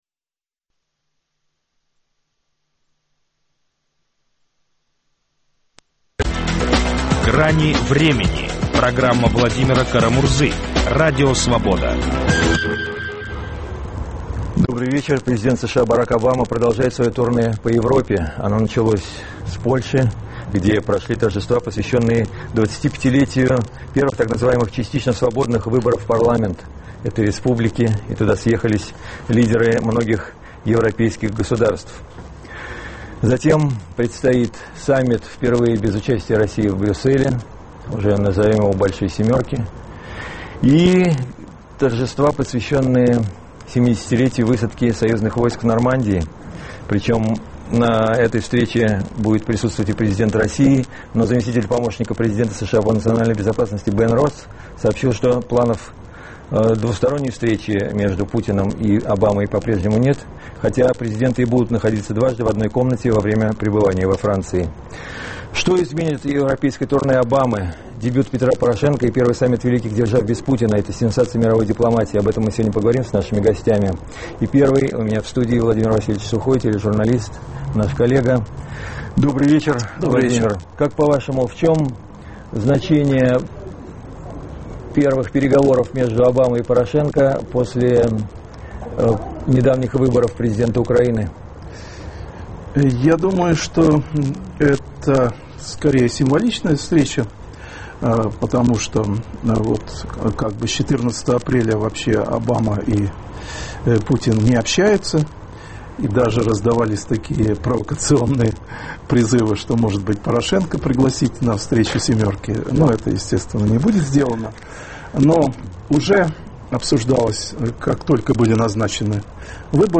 Что изменит европейское турне Обамы? Дебют Петра Порошенко и первый саммит великих держав без Путина - сенсации мировой дипломатии. В эфире - журналисты